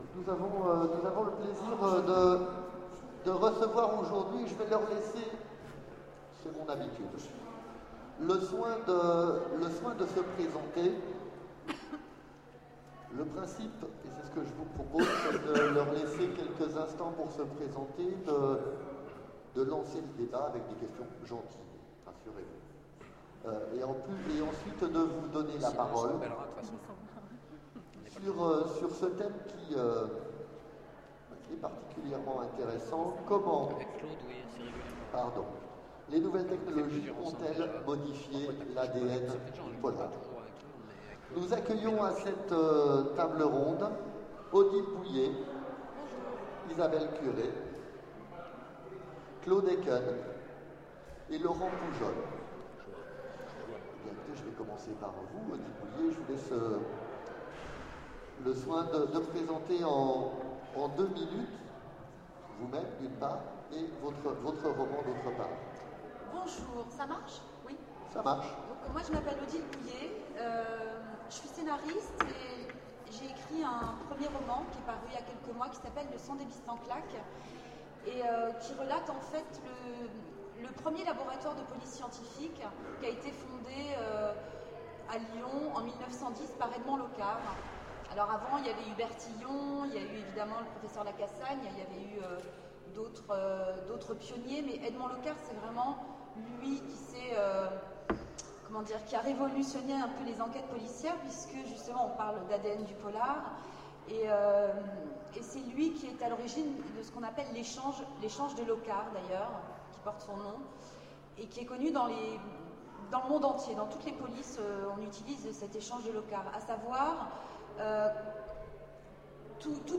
Roman(s) sur Romans : Conférence Les nouvelles technologies ont-elles modifié l’ADN du polar ?
Mots-clés Actes de colloque Partager cet article